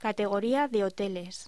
Locución: Categoría de hoteles
voz